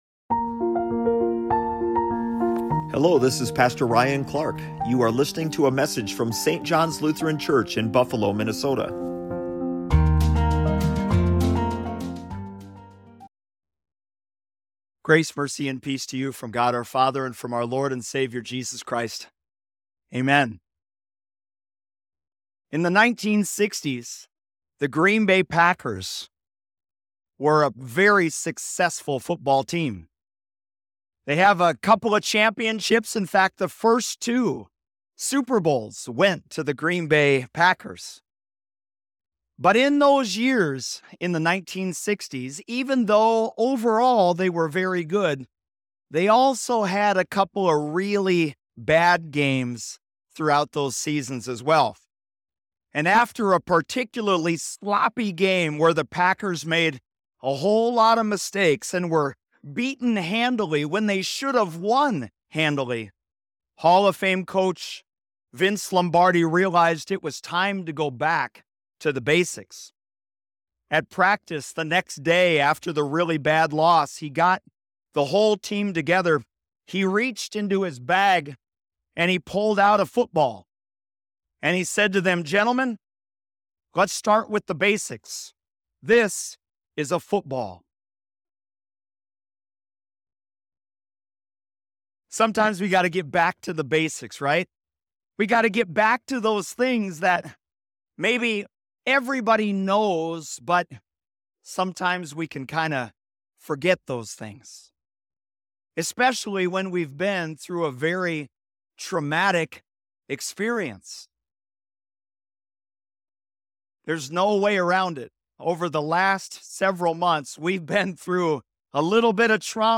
Listen to the latest sermon from St. John's Lutheran Church.